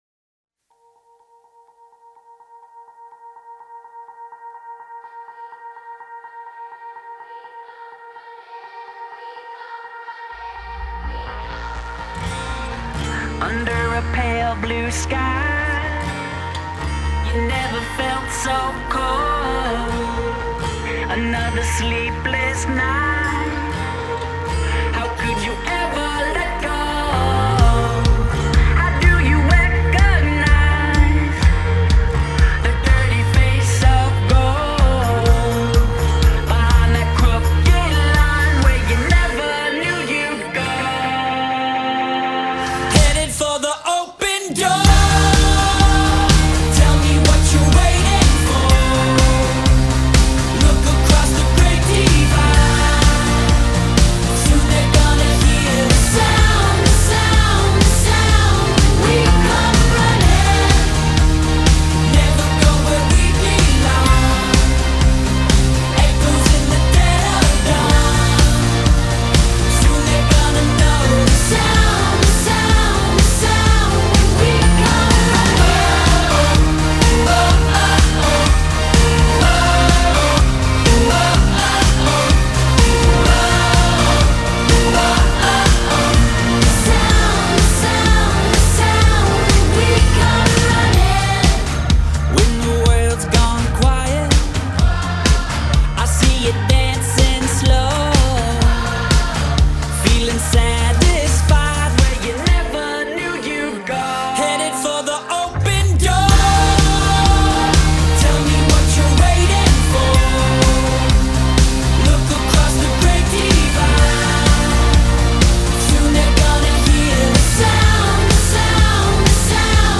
Punchy energy.  Catchy.  Good times.